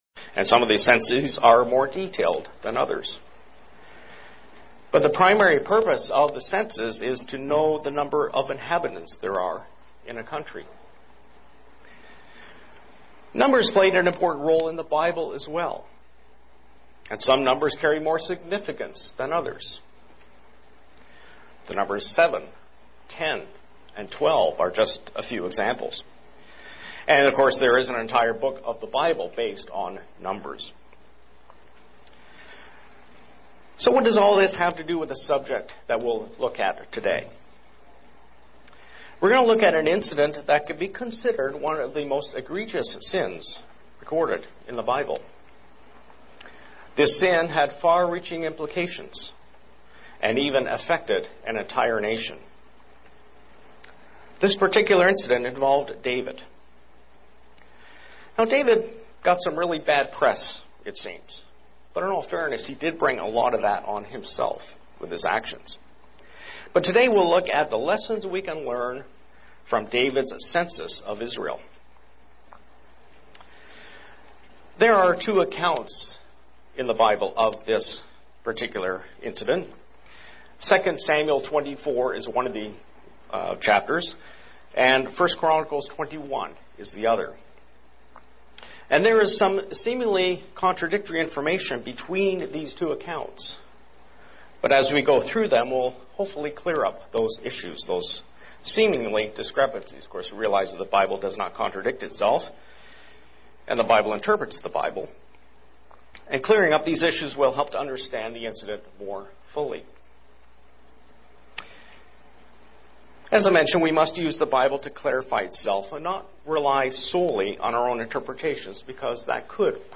UCG Sermon